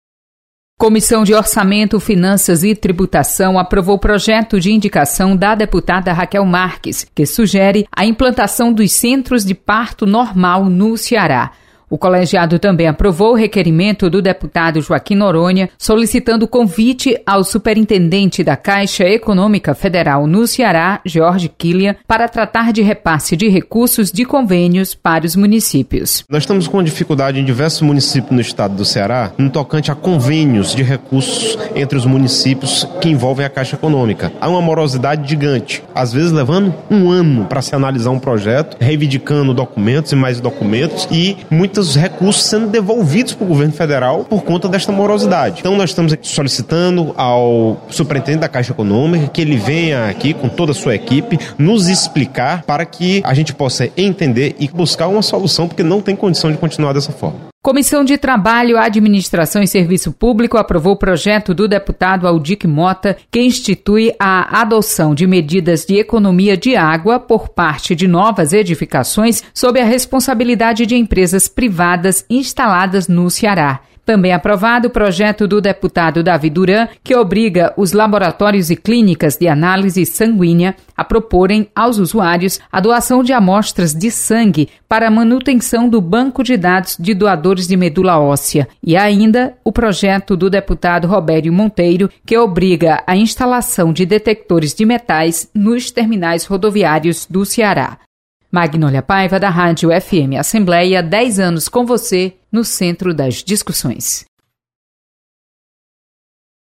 Acompanhe resumo das comissões técnicas permanentes da Assembleia Legislativa. Repórter